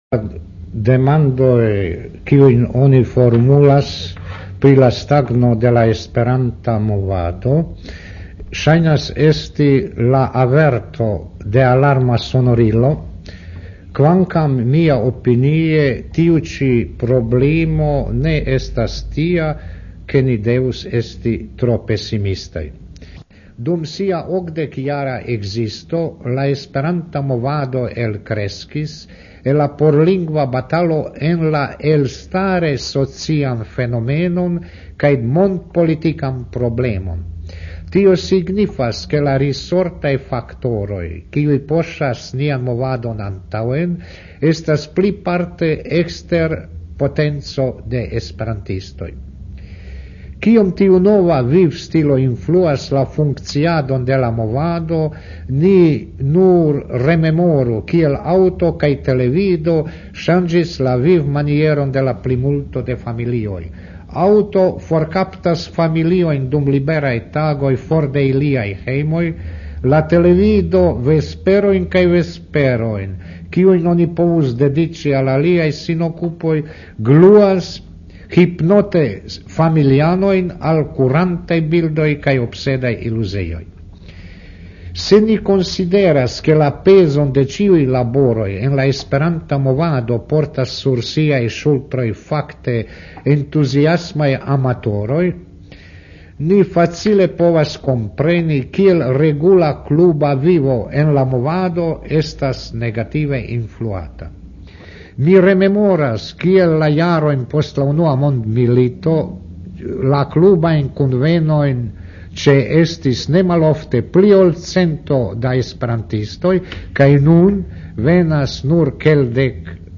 (liaj intervjuoj de diversaj homoj por esperantaj elsendoj de Radio-Zagreb 1970-2000)
(Snimci su u Mp3-formatu, ovdje veoma sažeti radi uštede prostora na štetu kvalitete)
(La dosieroj estas en la Mp3-formato, tre malgrandigitaj pro ŝparado de spaco, sed tial malpli kvalitaj)